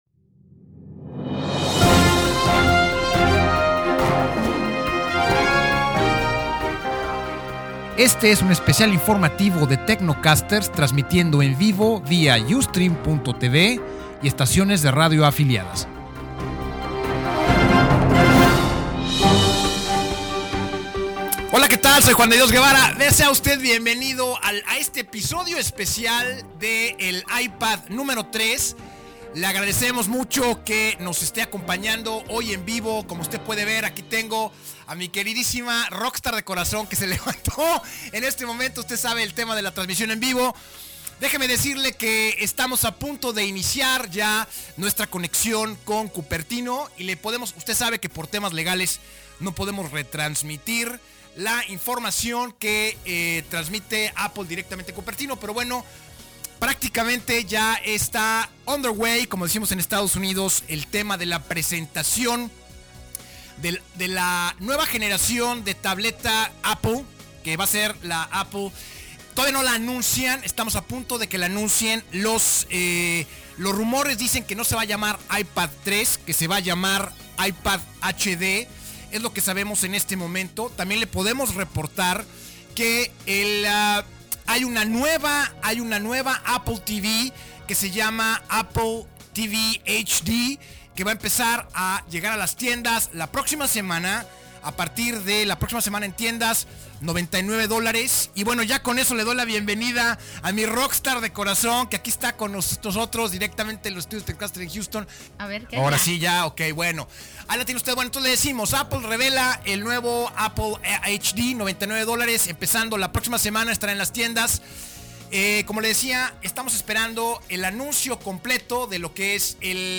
La transmision especial de TecnoCasters en vivo sobre el lanzamiento del Ipad nueva generacion.